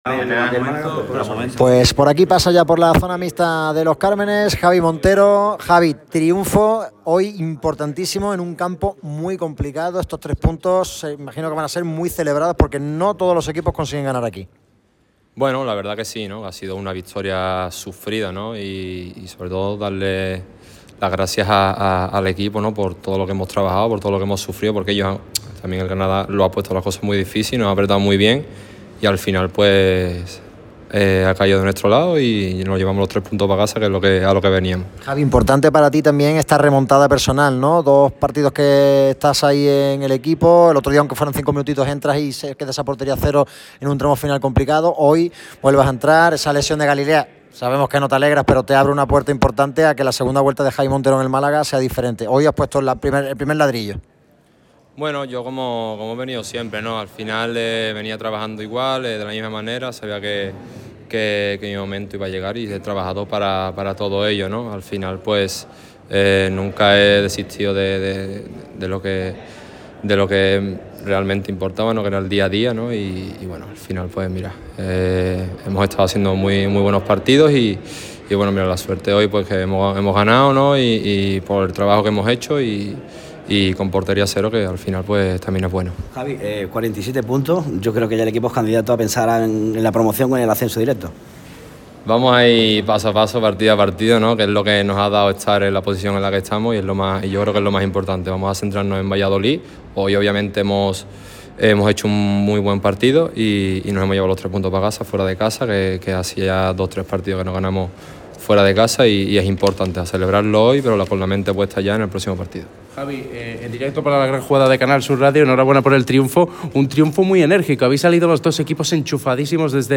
Tras el partido, Montero atendió a los medios en zona mixta.
Declaraciones de Javi Montero